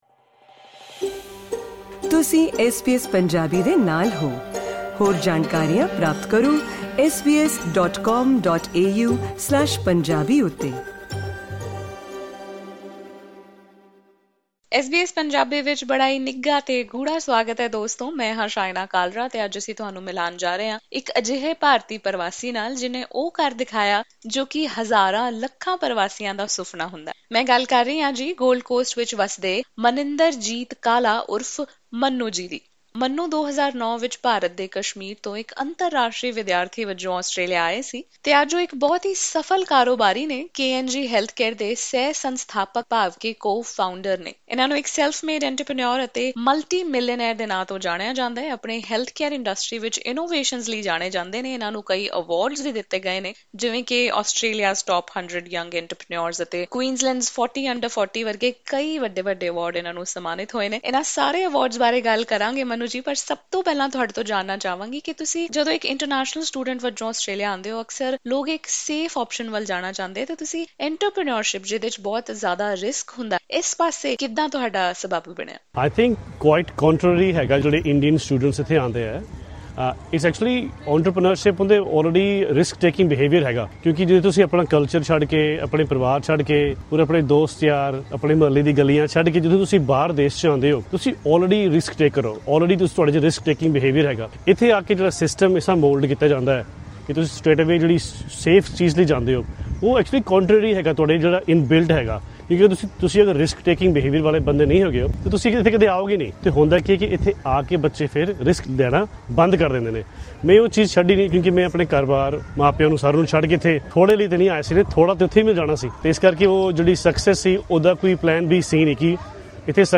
conversation with SBS Punjabi